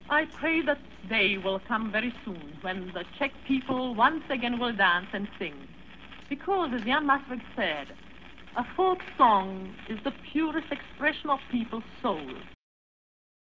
A blind women sings for money on Karluv Most--Charles Bridge-- while her husband controls the cassette player.